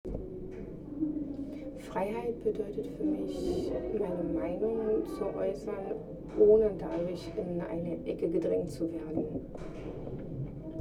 MS Wissenschaft @ Diverse Häfen
Standort war das Wechselnde Häfen in Deutschland.